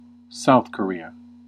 Ääntäminen
Synonyymit Republic of Korea Ääntäminen US : IPA : [ˌsaʊθ kə.ˈɹi.ə] Lyhenteet ja supistumat (laki) S. Kor.